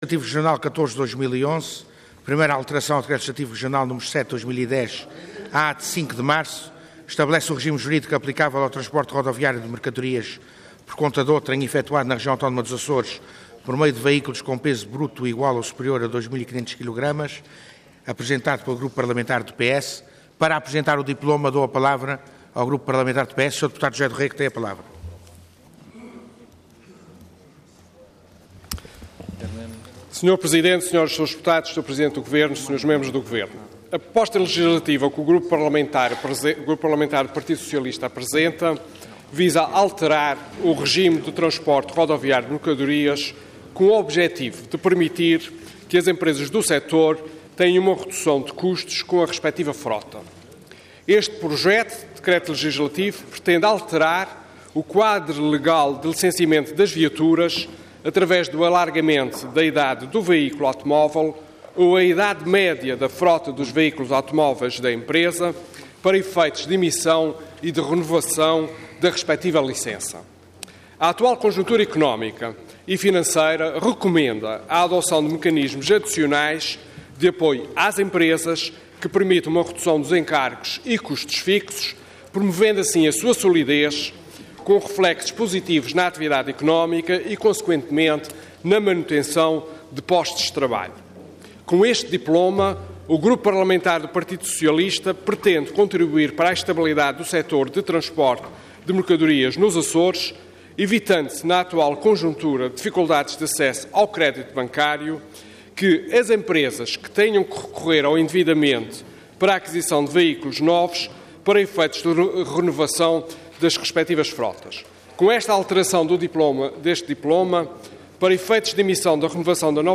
Assembleia Legislativa da Região Autónoma dos Açores
Intervenção
José Rego
Deputado